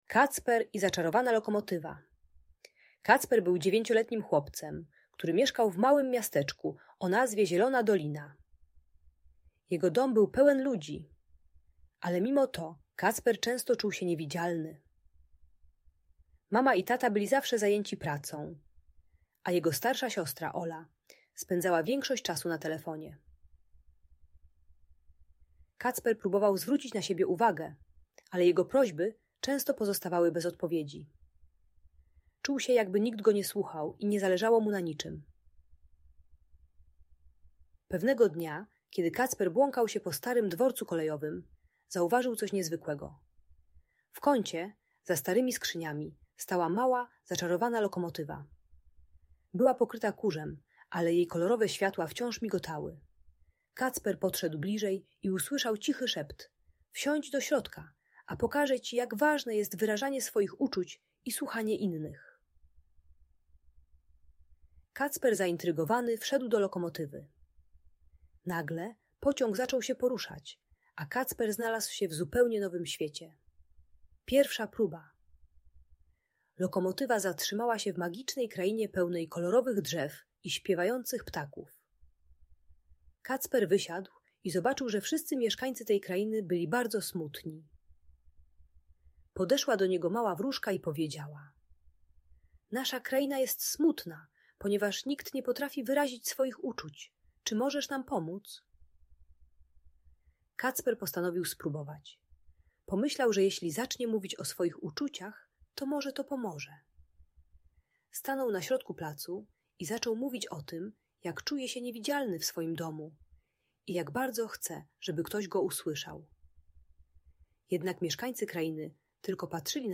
Kacper i Zaczarowana Lokomotywa - Magiczna historia - Audiobajka